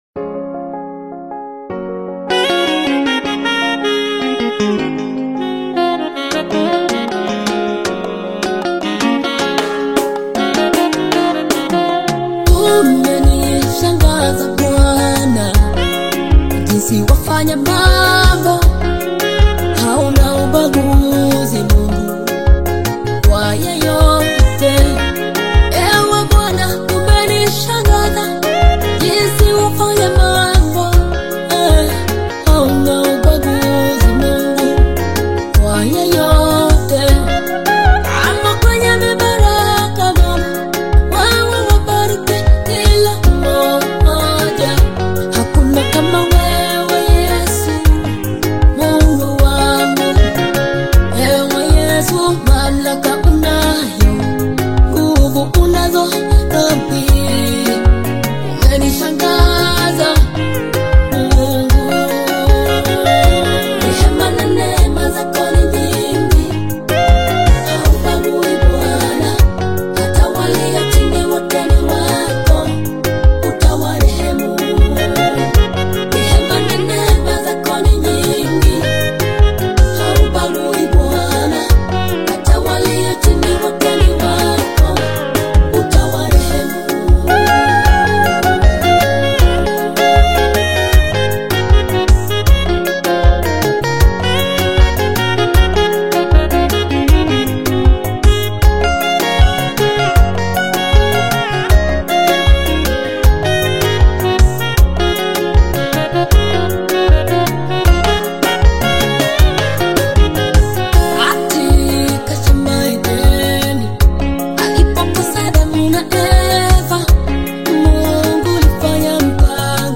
AudioGospelKenyan Music
heartfelt Gospel/Afro-Worship single
passionate vocals